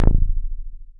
DOWN BASS A1.wav